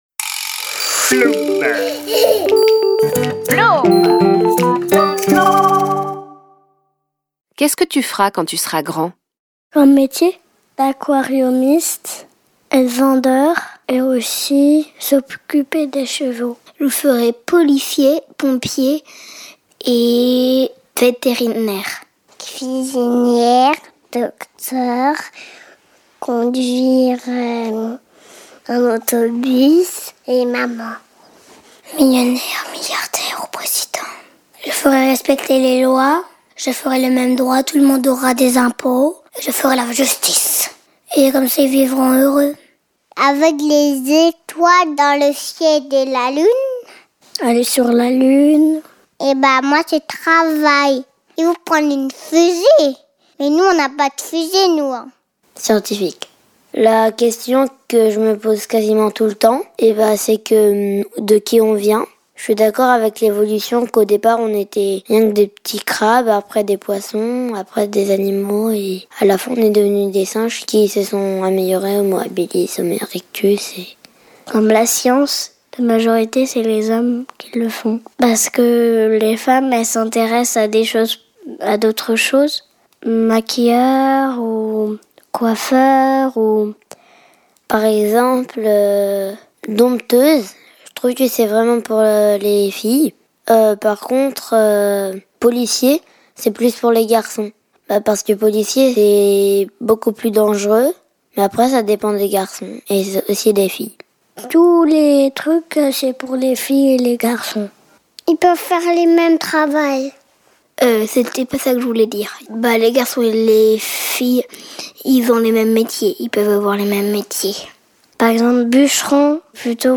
Diffusion distribution ebook et livre audio - Catalogue livres numériques
01/07/2022 La collection Bienvenue dans le futur, propose aux enfants de sauter à pieds joints dans le monde de demain. Au travers de contes d'anticipation, de jeu, de chanson, et d'émissions.